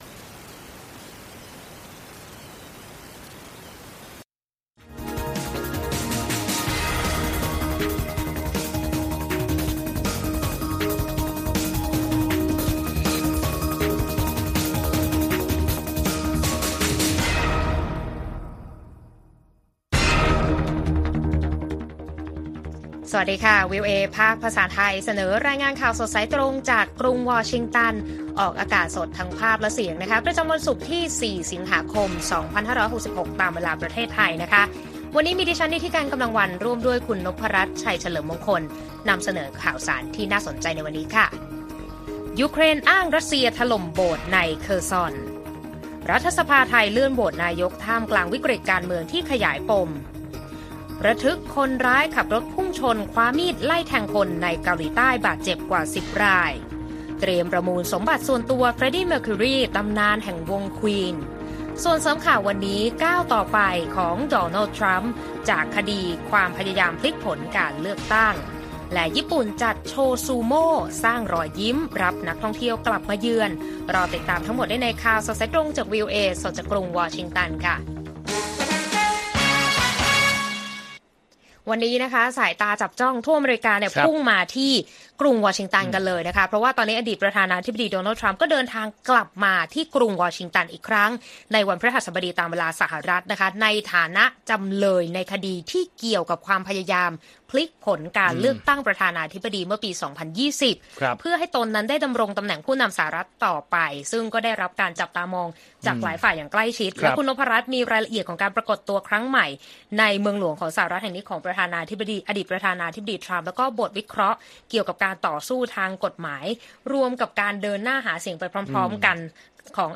ข่าวสดสายตรงจากวีโอเอไทย 6:30 – 7:00 น. วันที่ 4 ส.ค. 2566